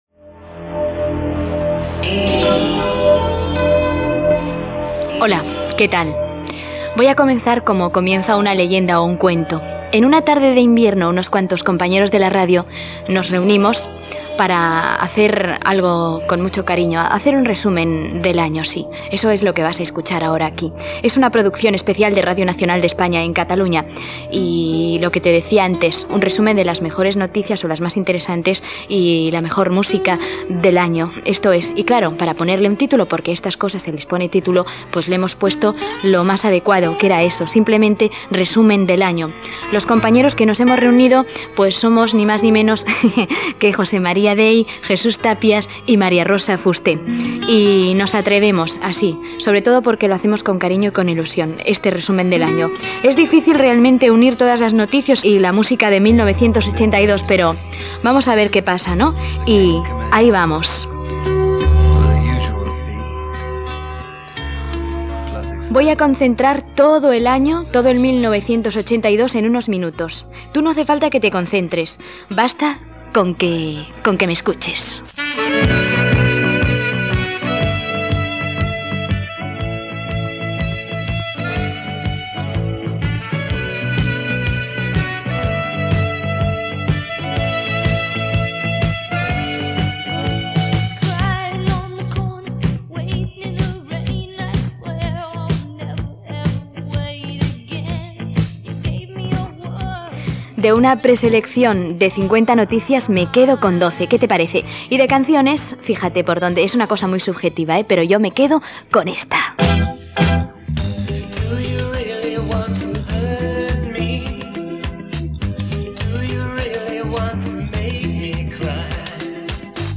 Presentació, tema musical, victòria a les leccions espanyoles del Partido Socialista Obrero Español (PSOE), amb declaracions de Felipe González, cançons de la campanya electoral: PSOE, Alianza Popular, Convergència i Unió
Informatiu